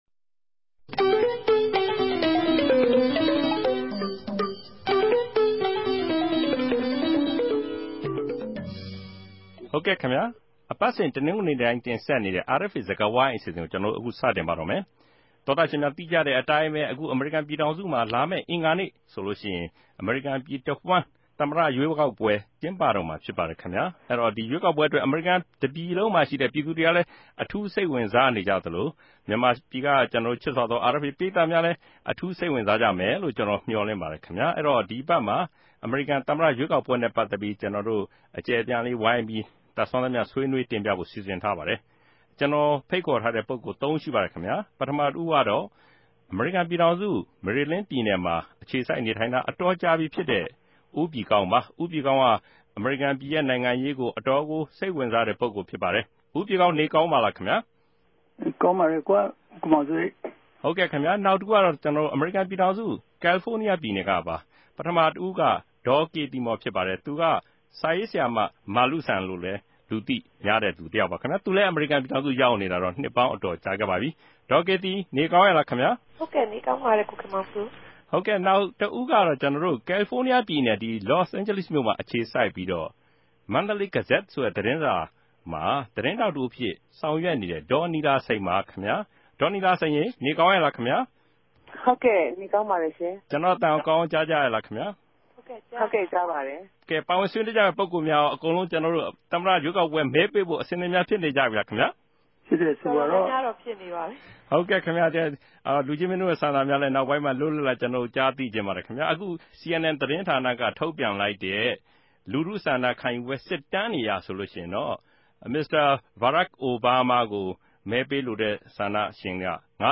ဒီအပတ်စကားဝိုင်းမြာ လာမယ့် အဂဿၝနေႛကဵင်းပတော့မယ့် အမေရိကန် သမတ ရြေးကောက်ပြဲနဲႛ ပတ်သက်္ဘပီး ဆြေးေိံြးတင်ူပထားပၝတယ်။
အမေရိကနိံိုင်ငံရောက် ူမန်မာ ၃ ဦးရဲႚ ရြူမင် သုံးသပ်ခဵက်တေကြို နားဆင်ပၝ။